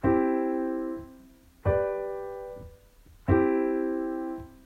I-V-I (Ganzschluss oder authentischer Schluss)
I-V-I-Ganzschluss.m4a